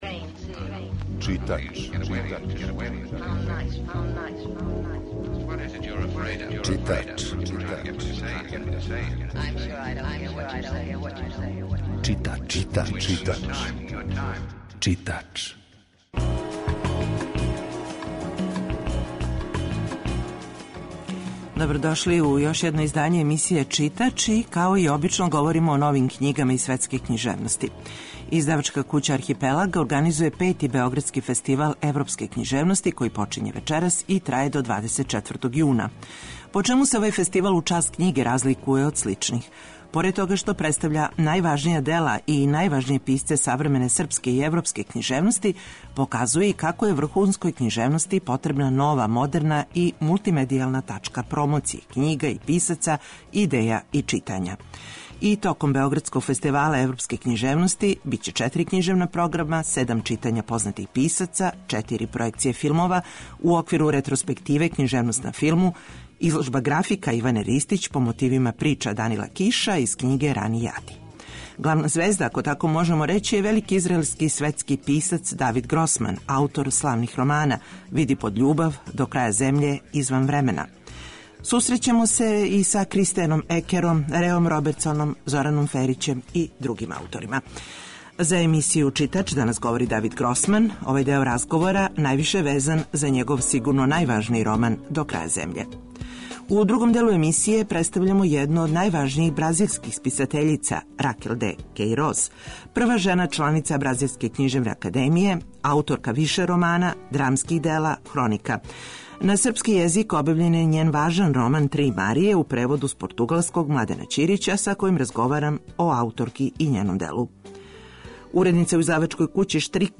Главна звезда овогодишњег фестивала је велики израелски и светски писац, Давид Гросман.
Емисија је колажног типа, али је њена основна концепција – прича о светској књижевности